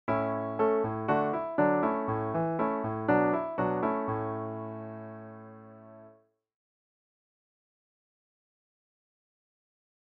To give you an idea of the effect we’re talking about, here are some simple short melodic fragments, first given in a major key, and then in a minor key equivalent:
A  D  A  Bm  A (
These are just midi files that I’ve posted here, so you’ll have to use your imagination and your own instrumentation to bring them to life.